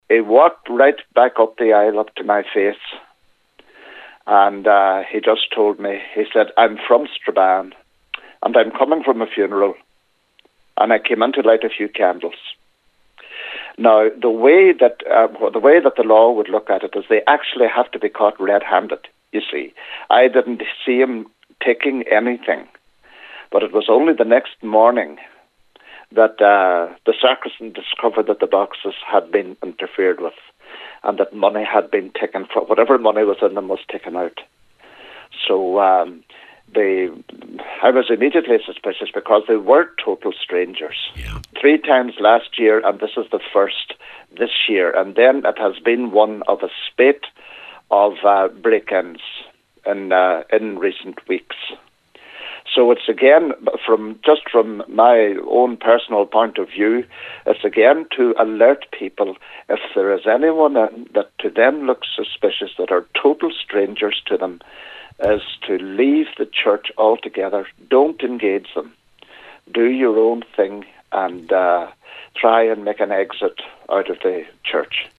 He spoke on today’s Nine Til Noon Show: